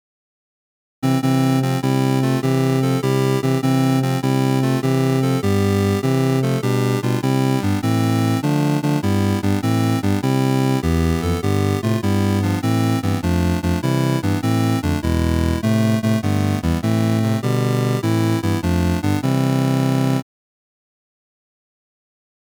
Early Synth-Wave Experiments